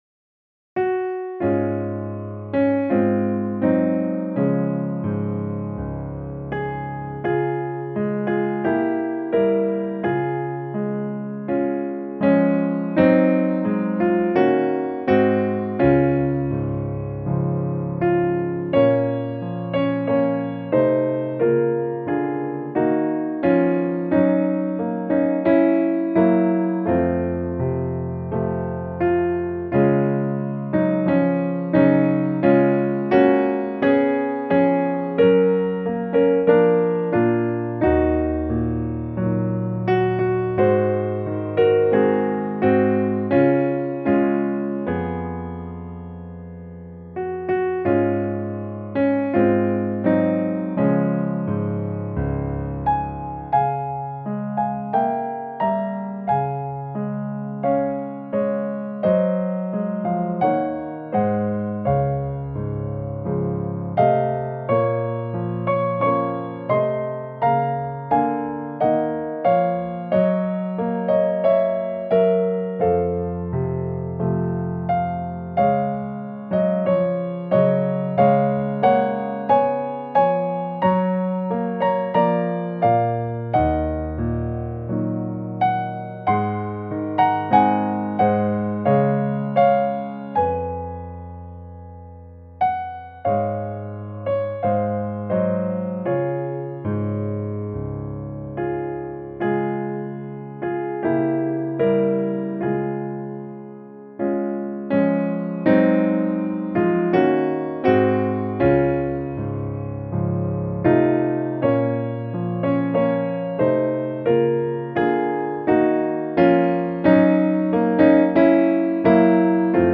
Hymn lyrics and .mp3 Download